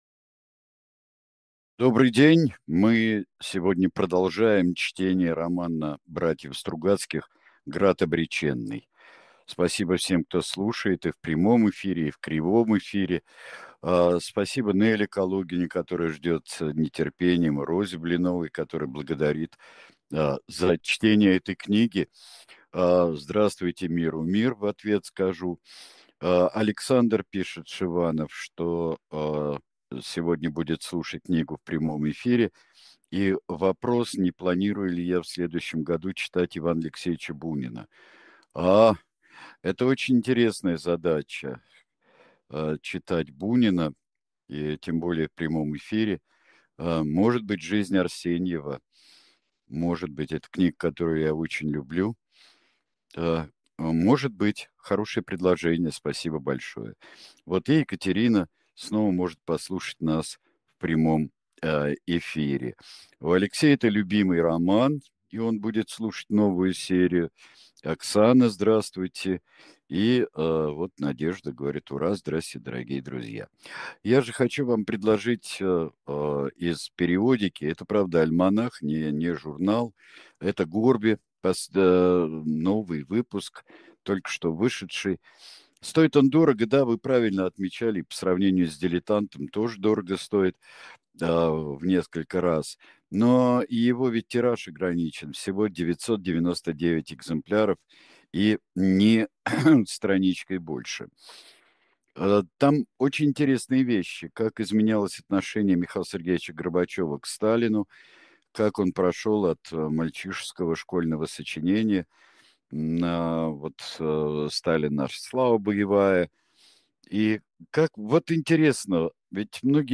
Читает произведение Сергей Бунтман